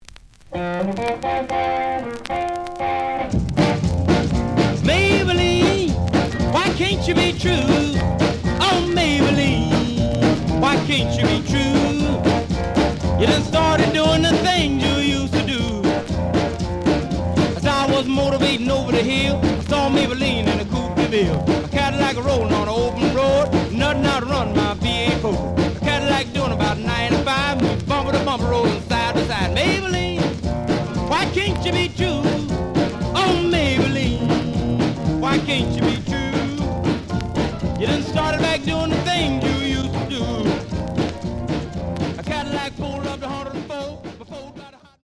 The audio sample is recorded from the actual item.
●Genre: Rhythm And Blues / Rock 'n' Roll
Slight noise on both sides.